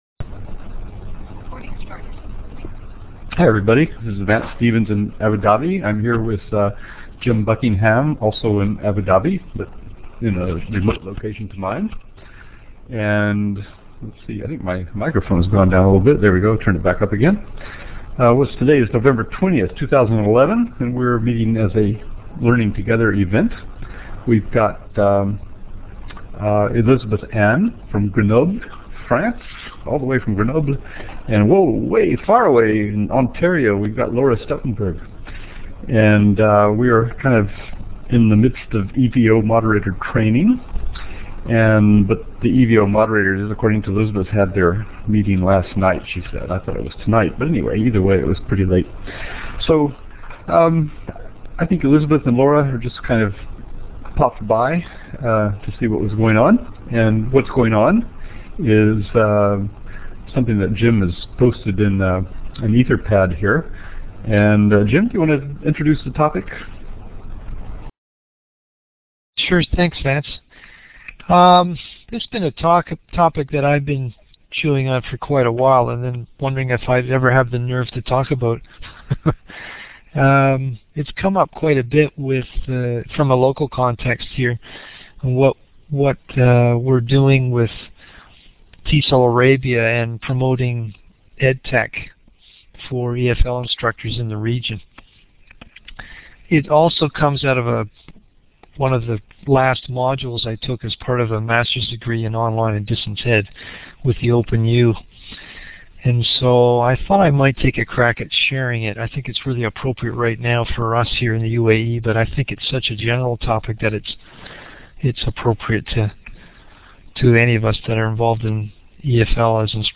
Elluminate discussion on ESOL professionalism